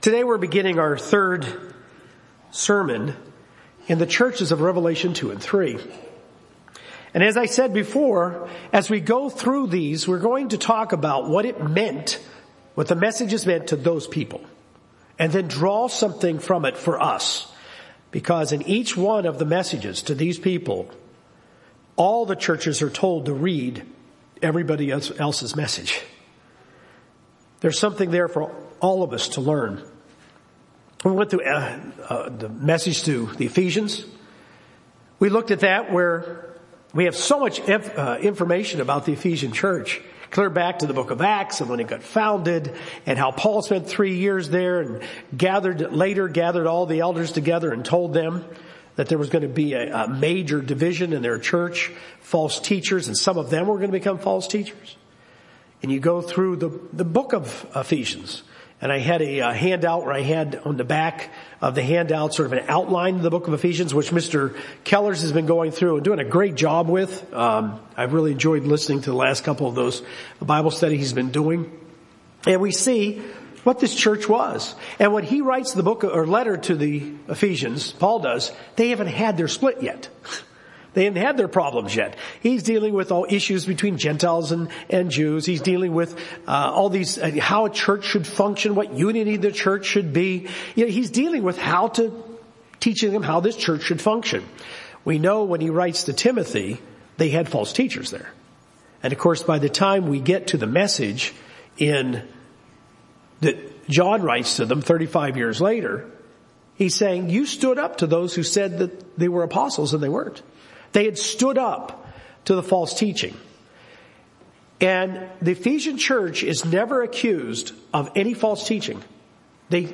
Jesus warns the church in Pergamos against the doctrine of Balaam and the teachings of the Nicolaitans. This sermon explains what the doctrine of Balaam is and why it’s so dangerous to Christianity.